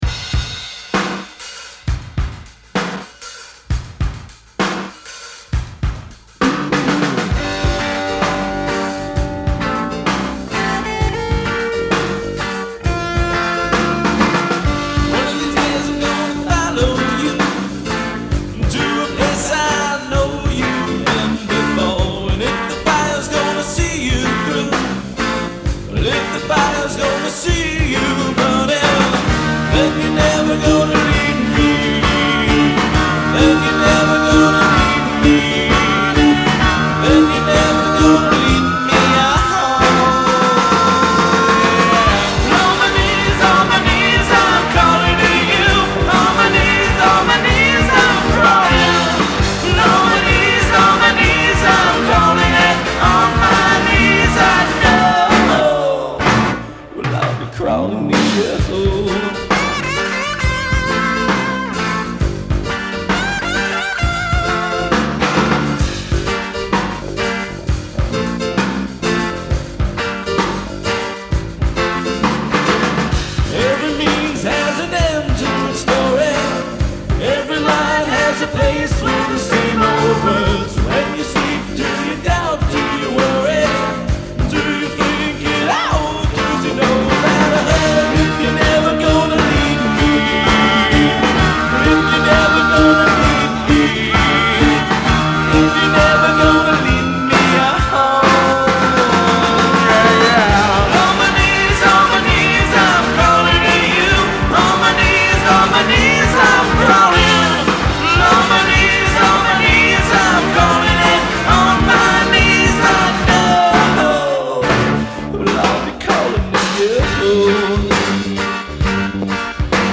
• (D) Sang Lead Vocals
• (E) Sang Backing Vocals
• (F) Played Drums
• (H) Played Keyboards